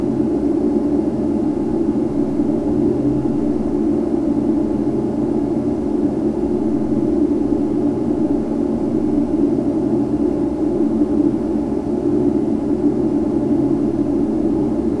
A320-family/Sounds/SASA/CFM56B/cockpit/cfm-comb.wav at cc30832e4915c9f4131a2f19c8fb778d7ceefe93